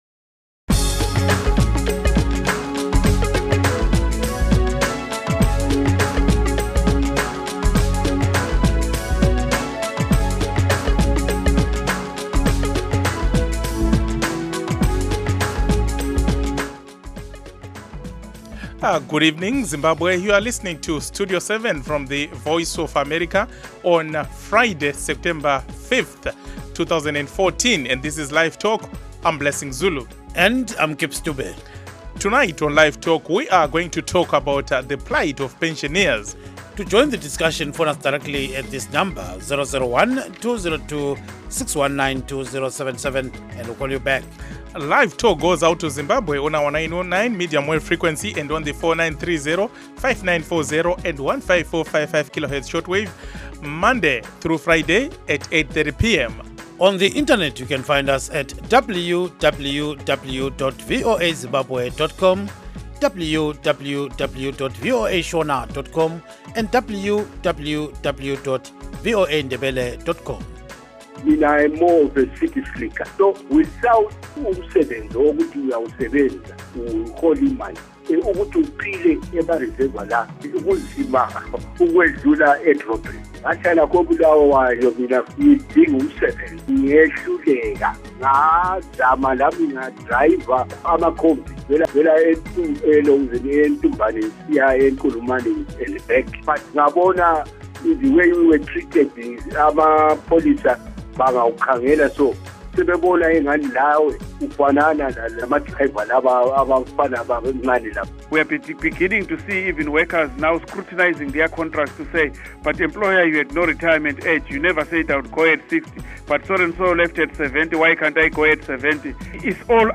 Zimbabweans living outside the country who cannot receive our broadcast signals can now listen to and participate in LiveTalk in real time.